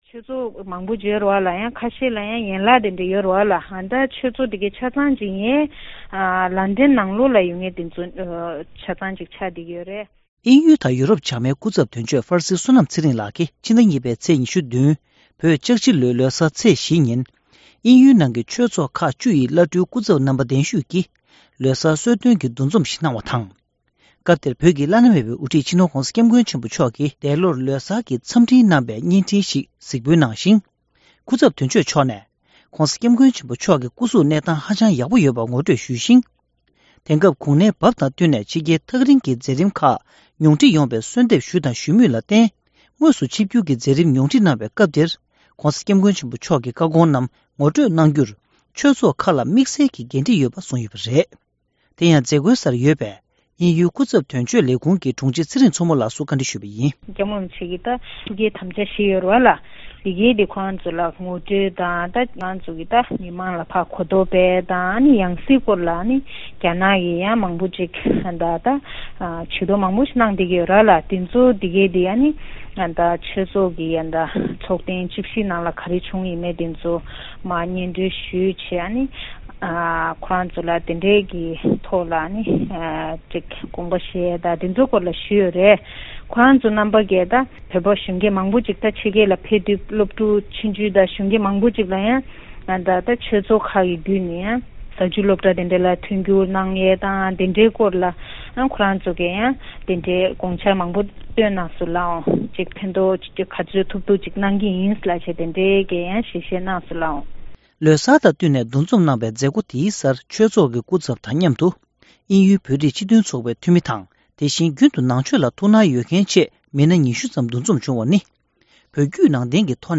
བཀའ་འདྲི་ཞུས་ནས་ཕྱོགས་སྒྲིག་ཞུས་པ་ཞིག་སྙན་སྒྲོན་ཞུ་གནང་གི་རེད།